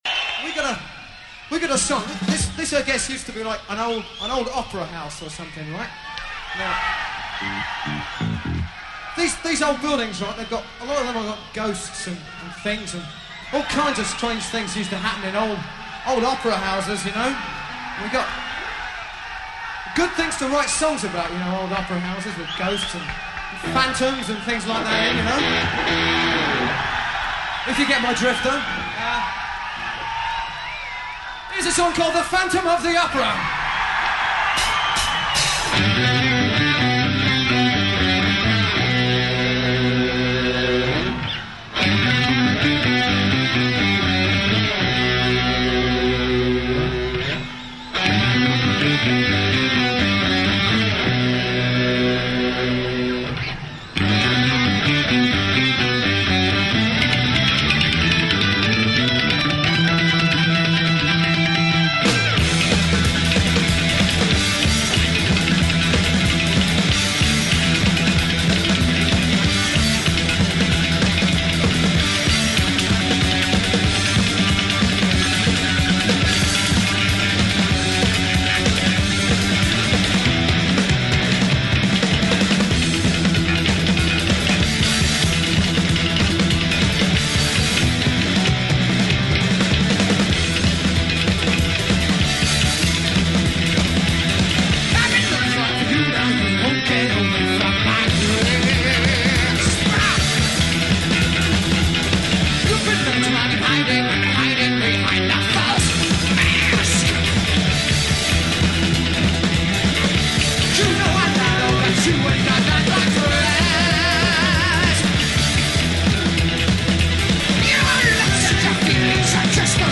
Live Concert NWOBHM